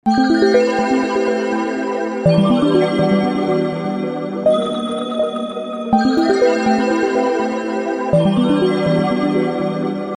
Creamy Keys, Beary Cute Glow!🥰🐻⌨